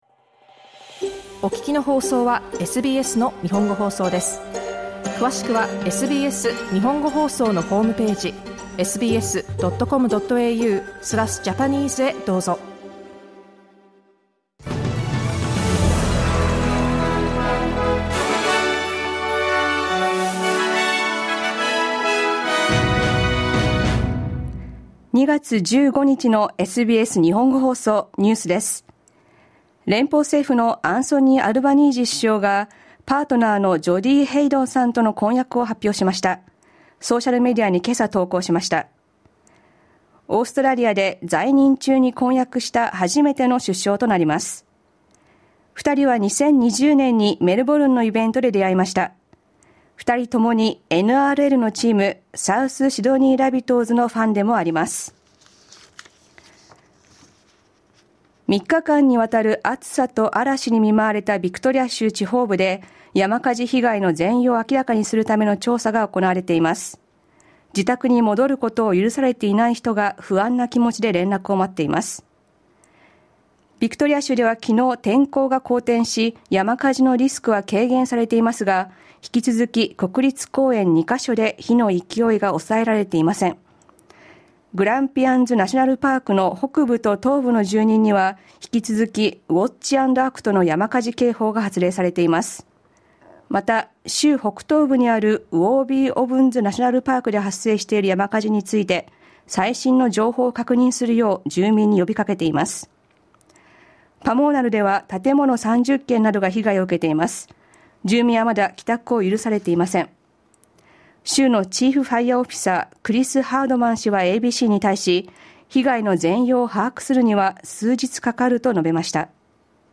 午後１時から放送されたラジオ番組のニュース部分をお届けします。